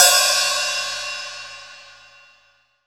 Index of /90_sSampleCDs/AKAI S6000 CD-ROM - Volume 3/Hi-Hat/STUDIO_HI_HAT